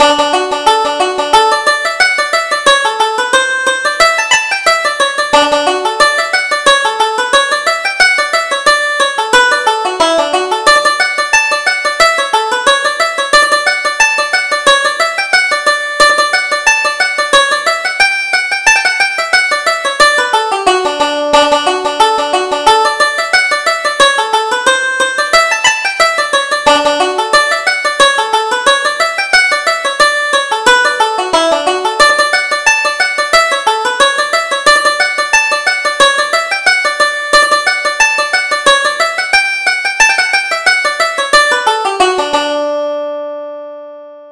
Reel: Jenny's Wedding